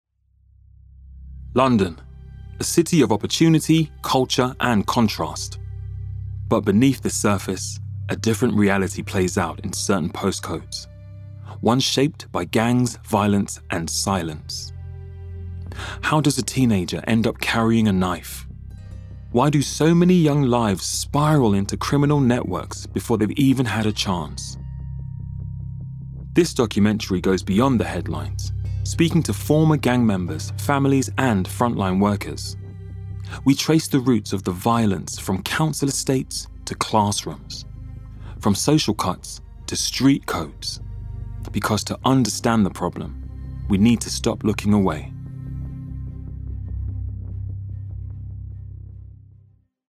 • Native Accent: London